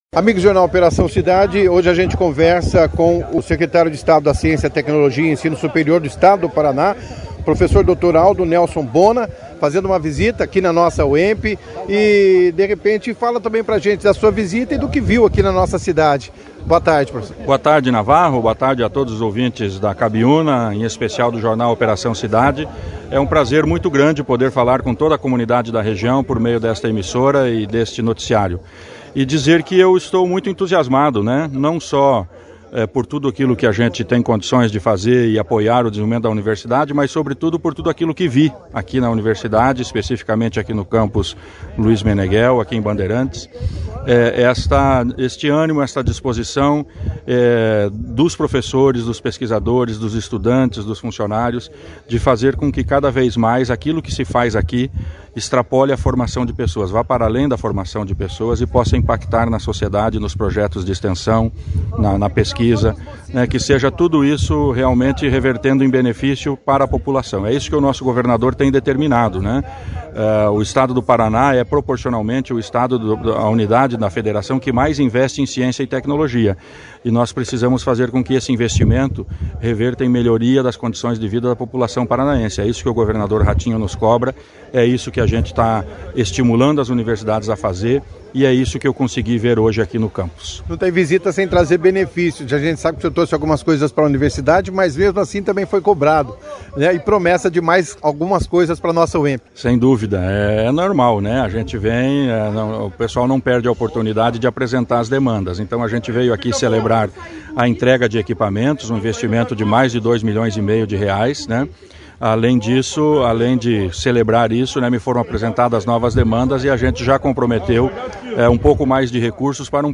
jornal Operação Cidade